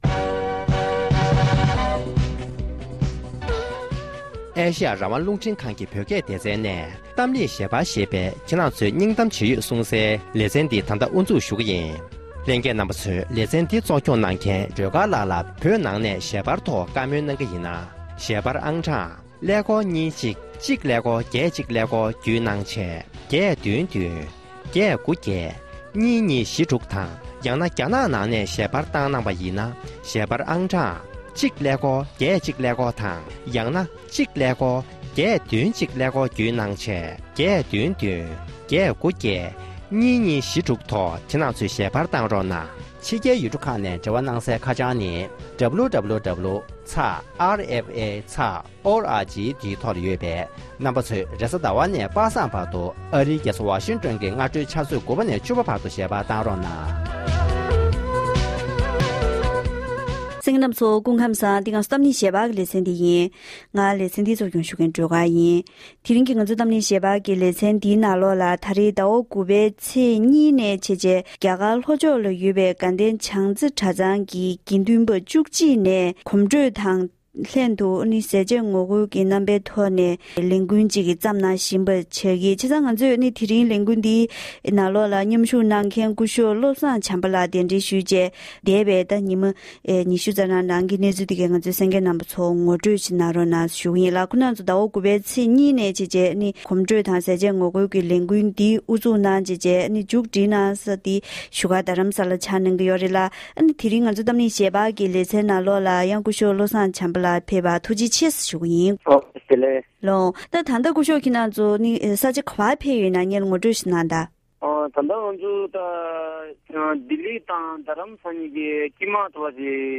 དེ་རིང་གི་གཏམ་གླེང་ཞལ་པར་གྱི་ལེ་ཚན་ནང་དུ